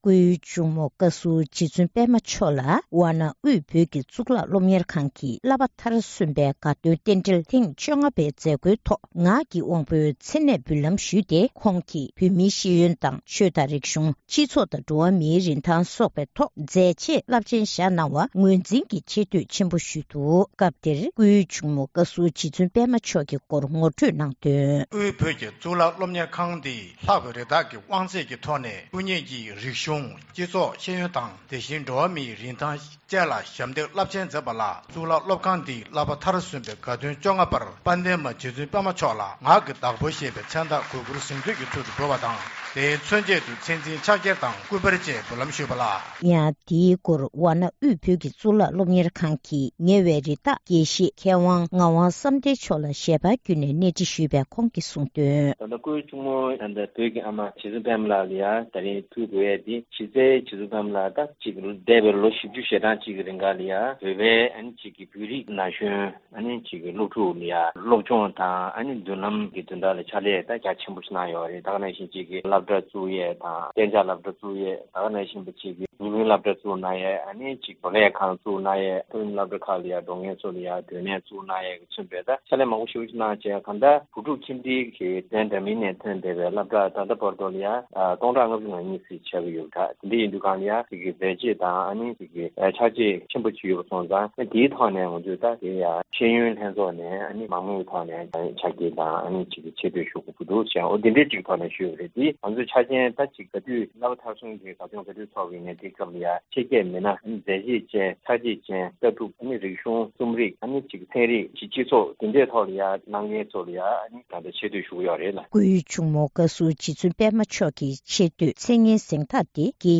༄༅། །གསར་འགྱུར་དང་འབྲེལ་བའི་ལེ་ཚན་ནང་།